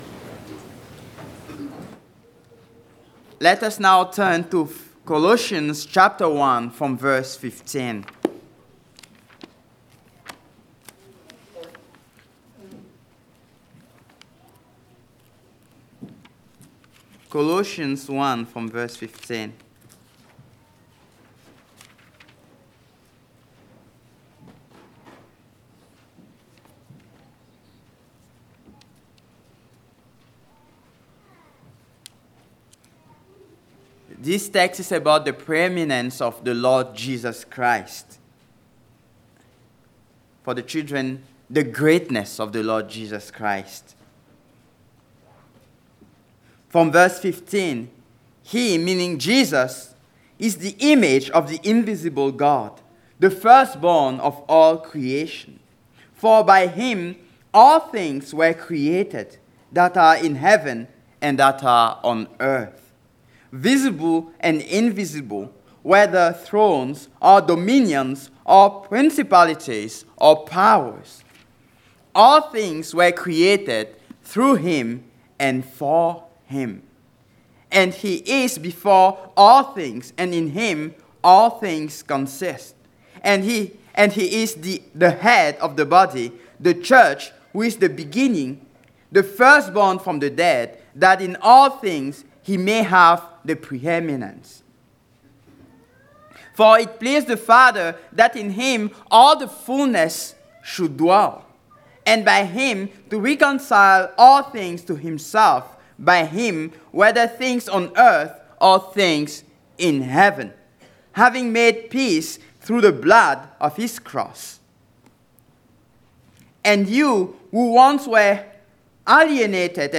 Passage: Hebrews 1:1-4 Service Type: Sunday Afternoon